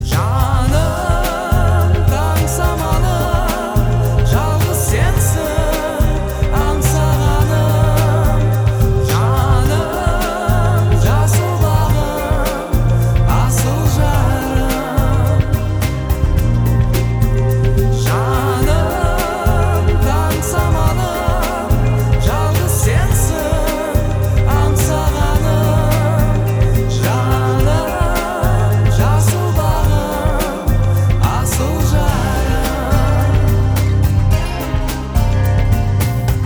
• Качество: 320, Stereo
красивые
мелодичные
спокойные
казахские
лиричные